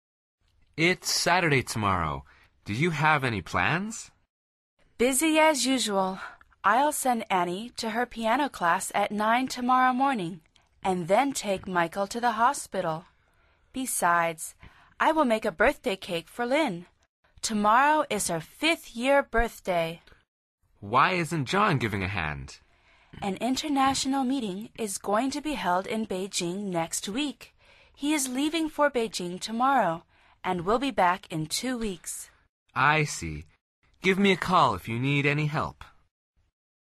Pulsa las flechas de reproducción para escuchar el primer diálogo de esta lección. Al final repite el diálogo en voz alta tratando de imitar la entonación de los locutores.